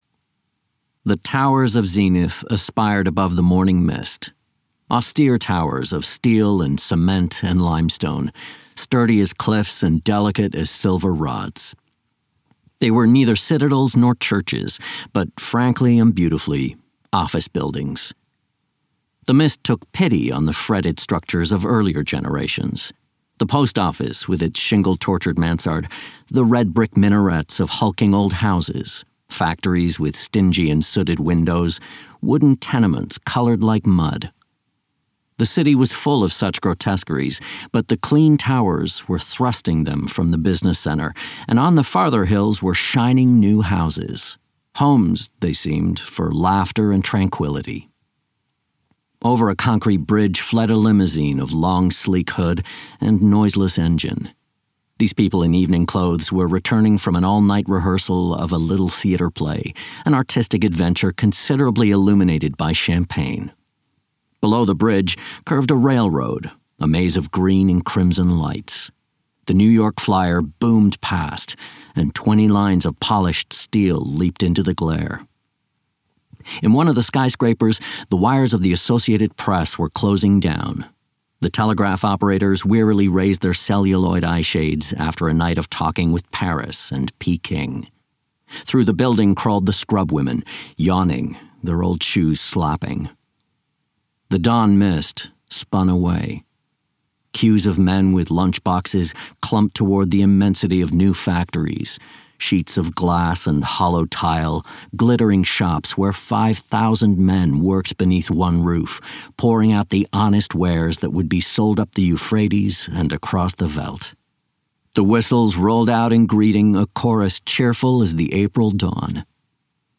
Audiobook Babbitt, written by Sinclair Lewis.
Audio knihaBabbitt
Ukázka z knihy